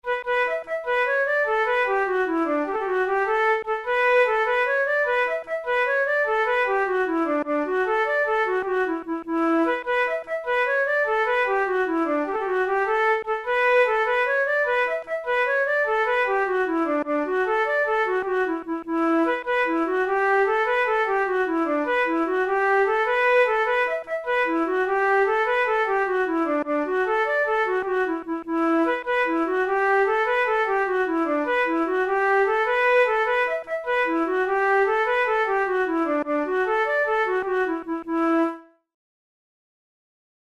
InstrumentationFlute solo
KeyE minor
Time signature6/8
Tempo100 BPM
Jigs, Traditional/Folk
Traditional Irish jig